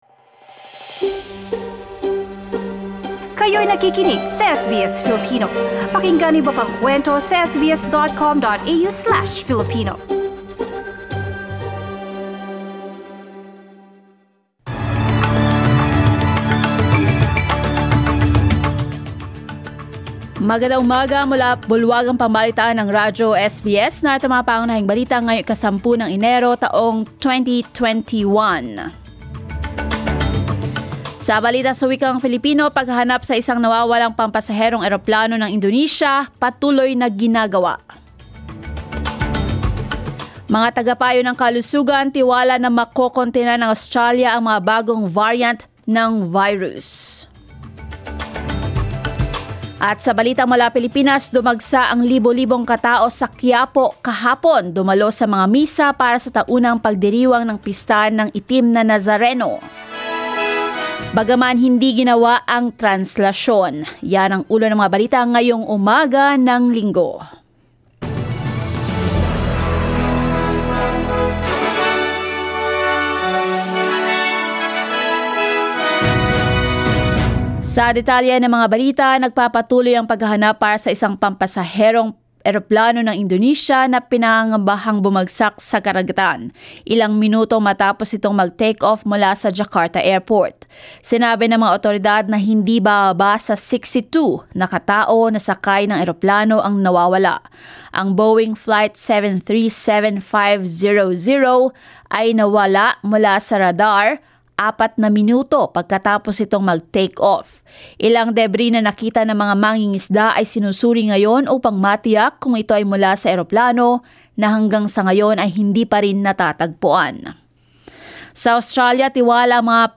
SBS News in Filipino, Sunday 10 January